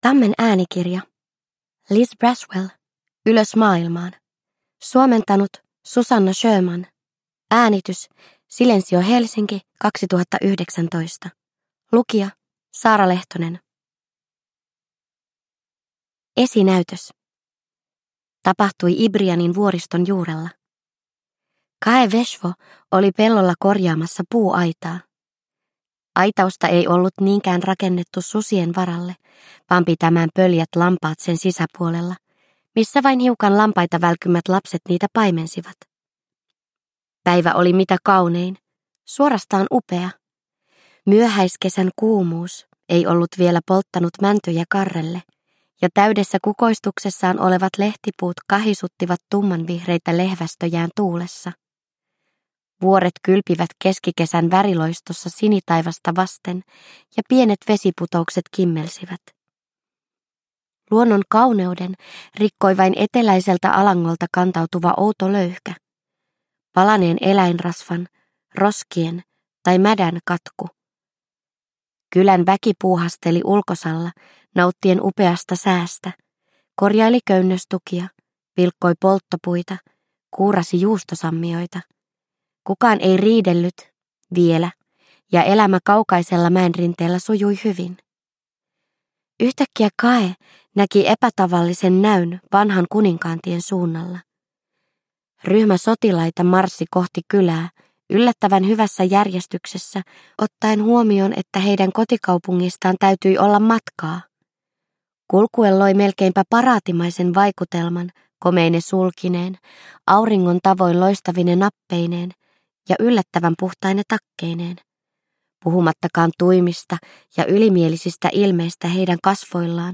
Ylös maailmaan. Twisted Tales – Ljudbok